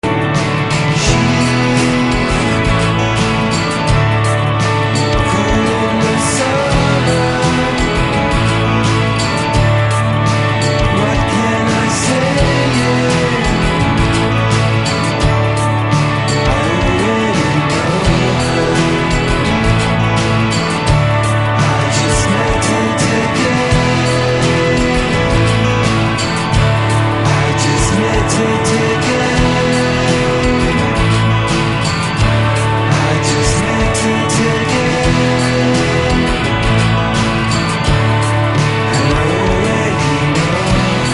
Recorded at La Maison (Bleu) and Hypnotech studios.